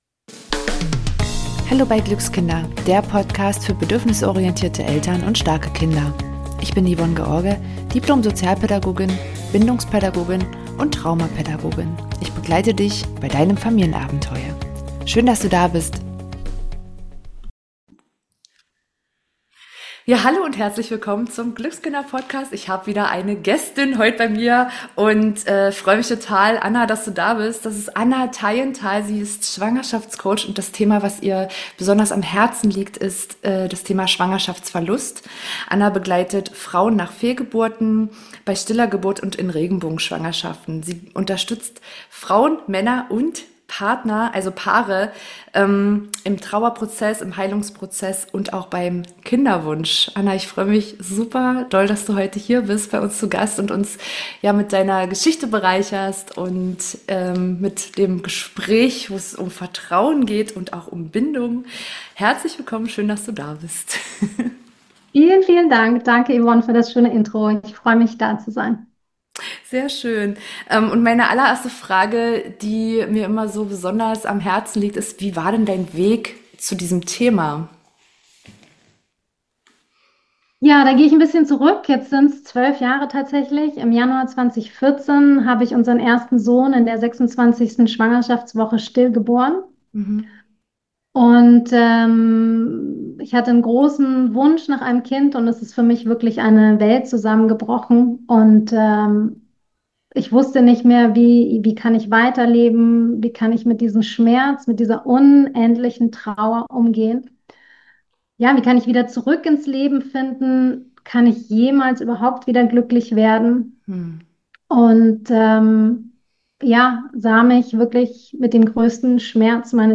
Ein ehrliches, warmes Gespräch über Verlust, Heilung, Bindung und die leise Kraft des Vertrauens.